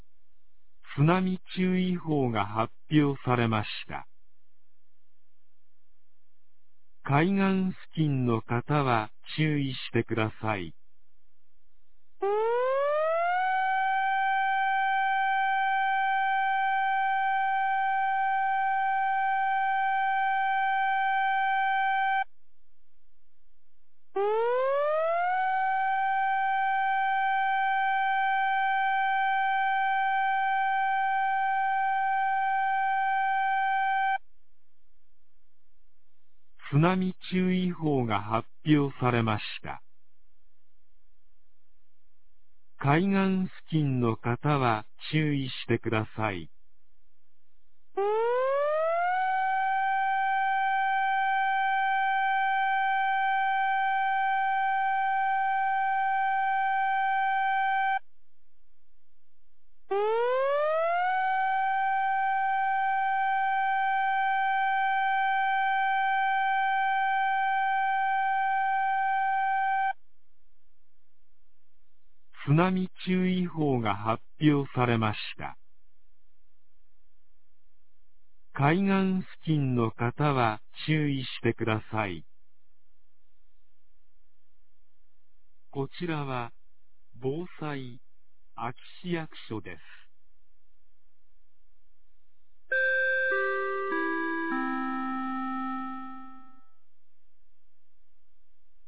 2023年10月09日 07時46分に、安芸市より全地区へ放送がありました。